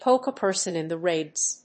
アクセントpóke [núdge, díg] a person in the ríbs